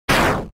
undertale hit Meme Sound Effect
undertale hit.mp3